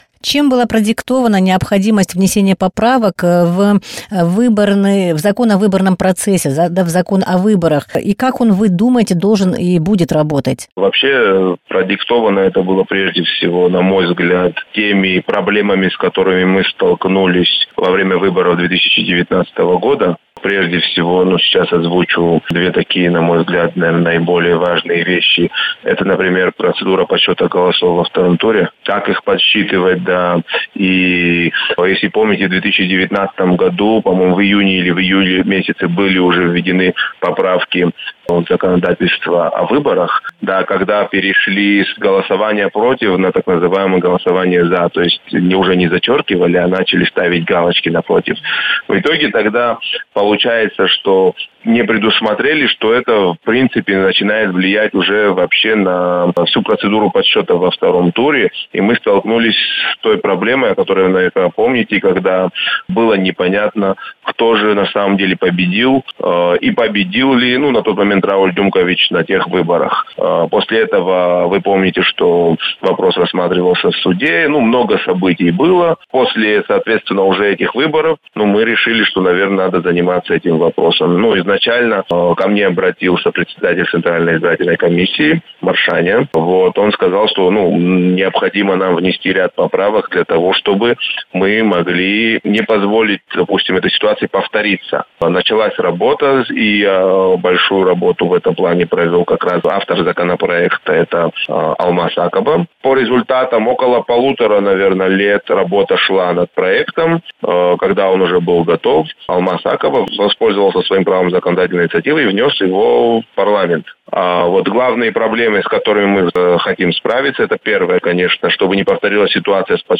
Депутат Парламента Даут Хутаба в интервью радио Sputnik рассказал, какие поправки ждет закон о выборах президента до ближайшего голосования.